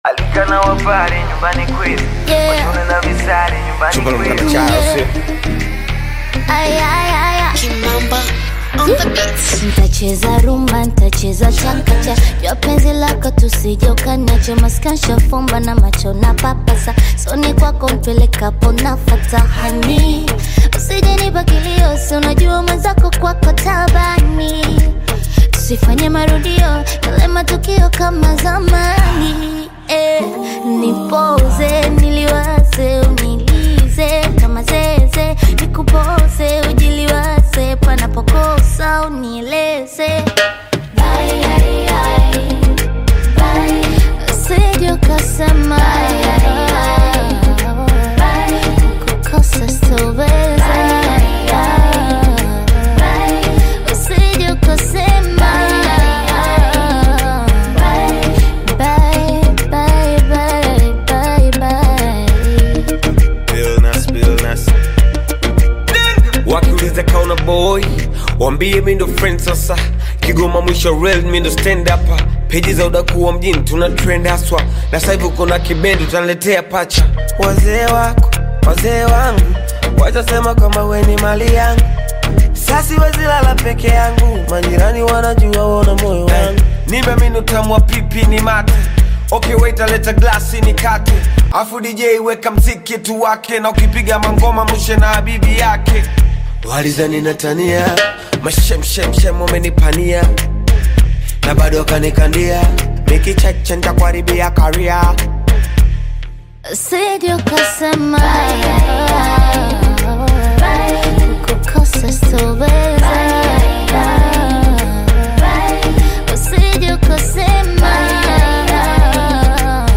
soulful Bongo Flava love single
produced with smooth Afrobeat and rumba-inspired sounds
Genre: Bongo Flava